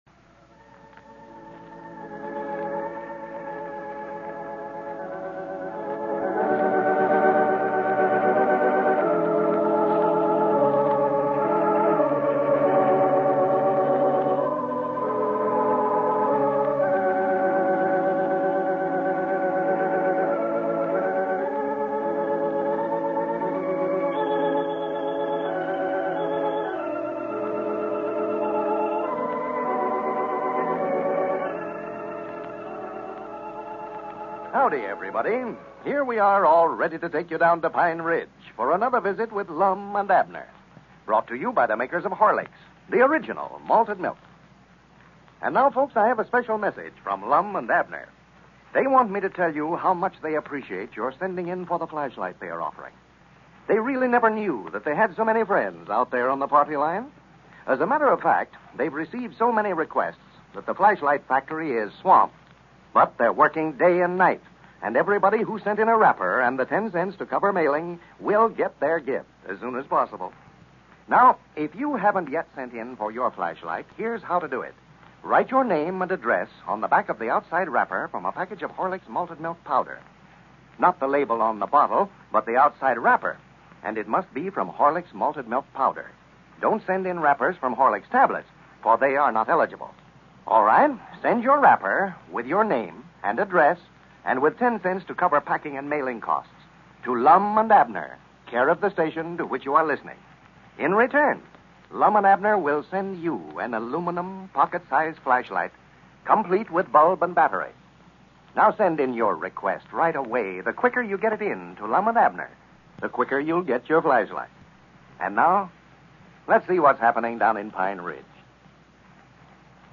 Lum and Abner! A classic radio show that brought laughter to millions of Americans from 1931 to 1954.